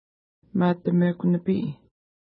Image Not Available ID: 93 Longitude: -61.7230 Latitude: 52.8232 Pronunciation: ma:təmeku-nəpi: Translation: Speckled Trout Lake Feature: lake Explanation: There are plenty of speckled trout in this lake.